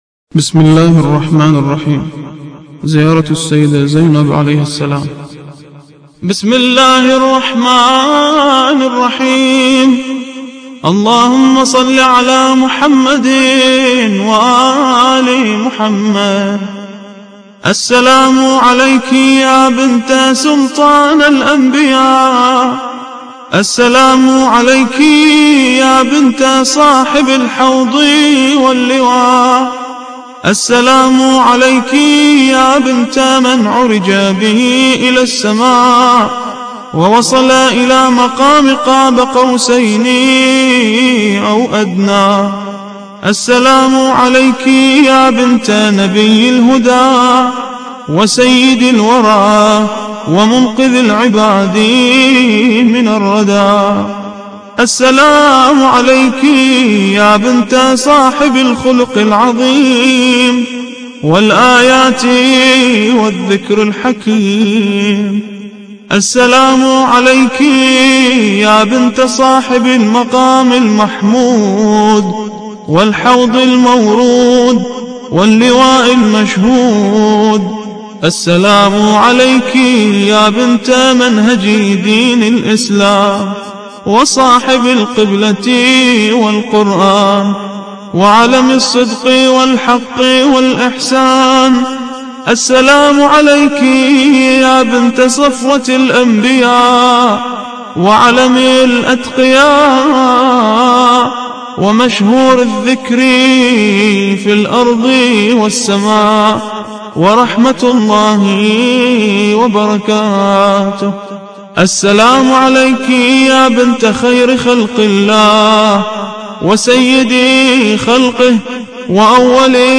زيارة السيدة زينب عليها السلام – الرادود